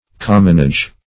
Commonage \Com"mon*age\, n. [Cf. OF. communage.]